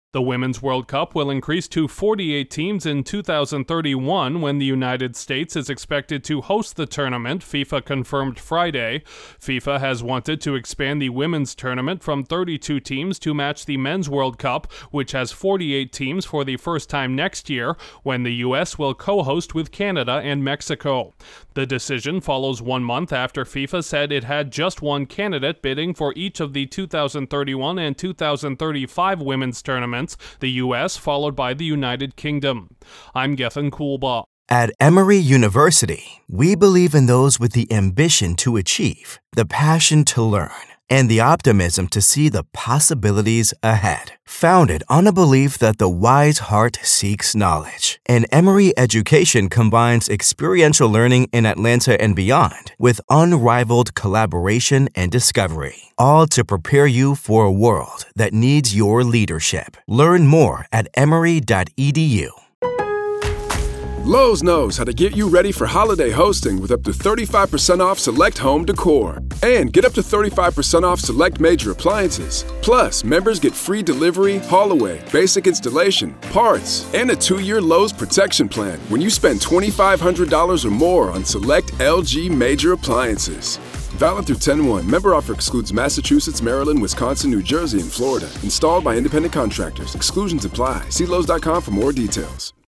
The Women’s World Cup is hoping to expand its field by 16 teams. Correspondent